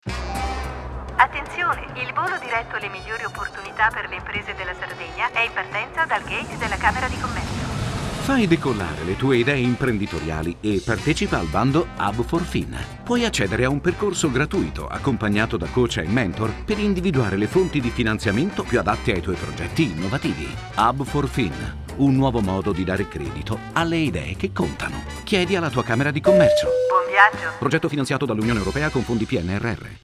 Lancio Bando – spot radio (mp3)
SPOT-RADIO-HUB4FIN-LANCIO-BANDO.mp3